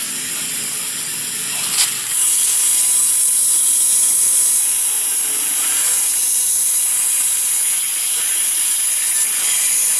Звуки радио и помехи
Повторяющиеся помехи
interference4.wav